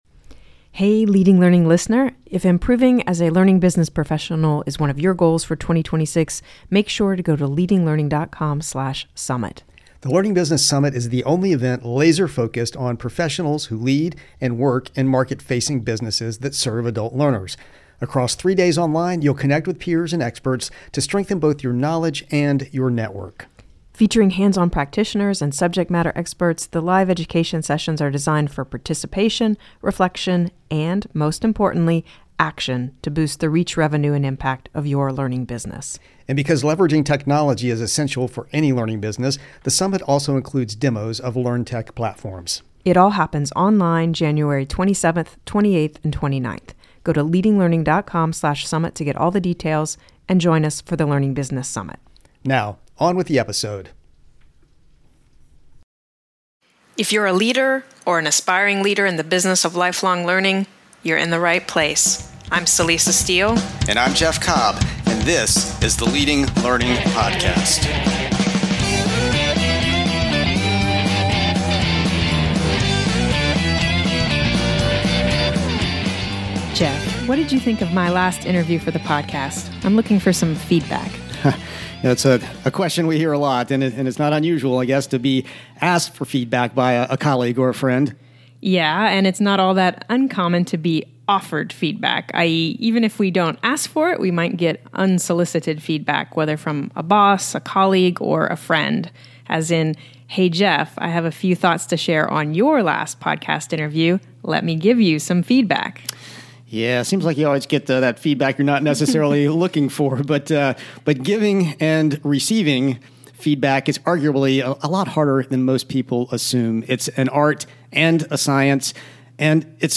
Note: We re-aired this episode interview in December 2023.